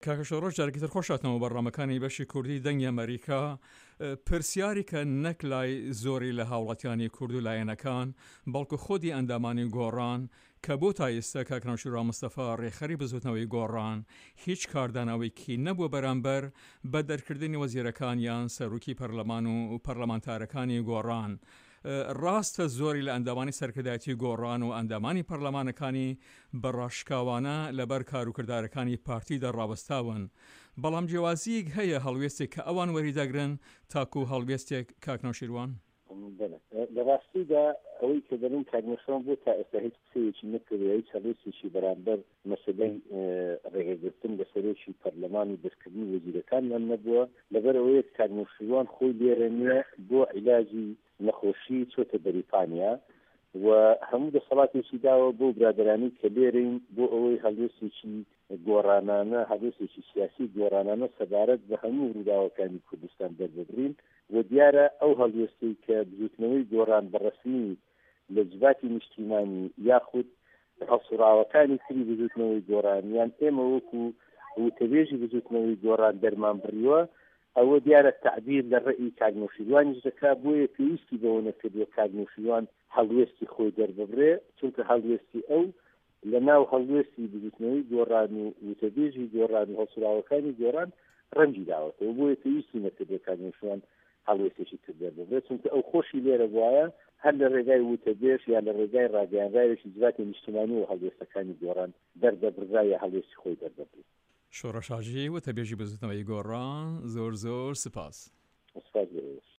هەڤپەیڤینێکدا